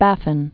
(băfĭn), William 1584?-1622.